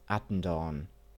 Attendorn (German: [ˈatn̩dɔʁn]
Attendorn.ogg.mp3